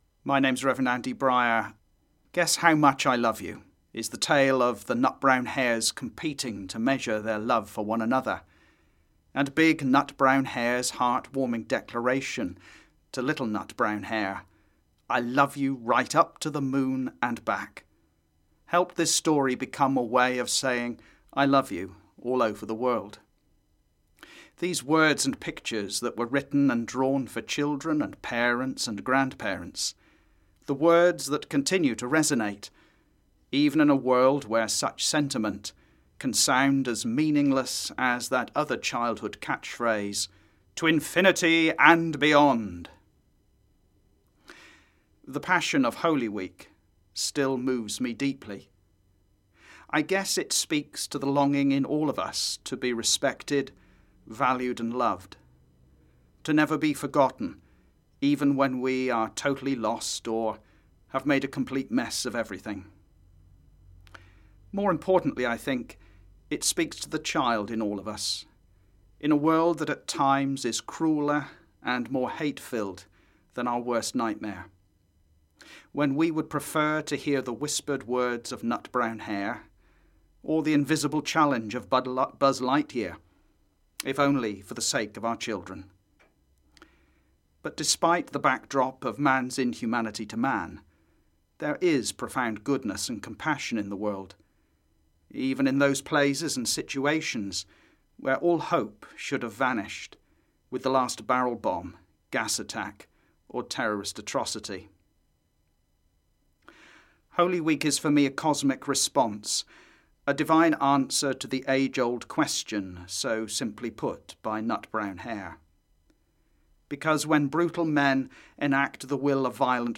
Good Friday message